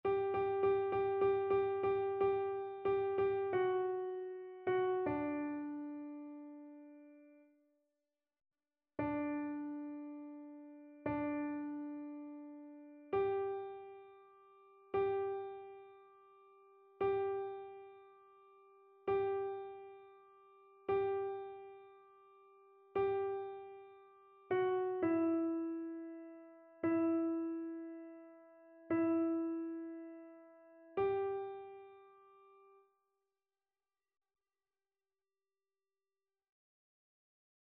Alto
annee-abc-fetes-et-solennites-saint-joseph-psaume-88-alto.mp3